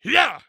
ZS普通攻击3.wav
ZS普通攻击3.wav 0:00.00 0:00.50 ZS普通攻击3.wav WAV · 43 KB · 單聲道 (1ch) 下载文件 本站所有音效均采用 CC0 授权 ，可免费用于商业与个人项目，无需署名。
人声采集素材/男3战士型/ZS普通攻击3.wav